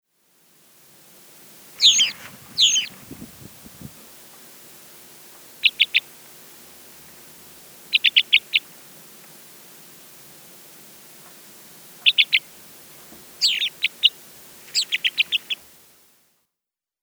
На этой странице собраны звуки вьюрка — мелодичные трели и позывки этой птицы.
Голос горного чёрного вьюрка